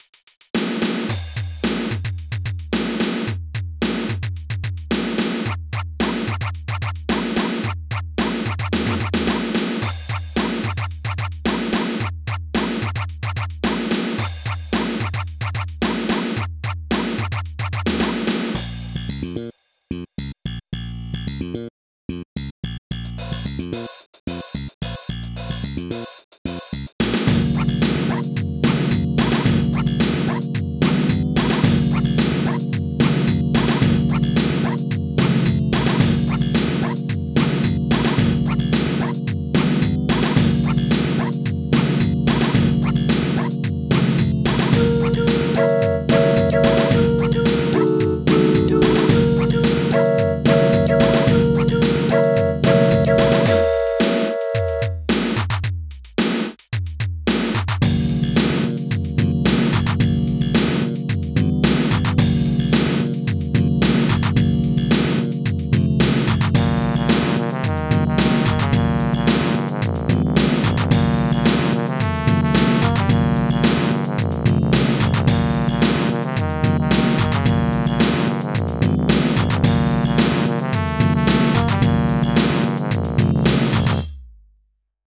made a pizza tower ish song in the midi